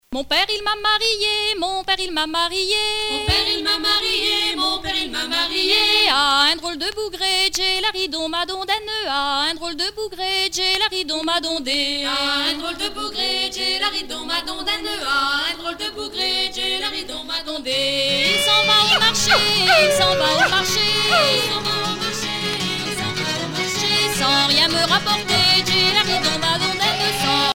danse : laridé, ridée
Pièce musicale éditée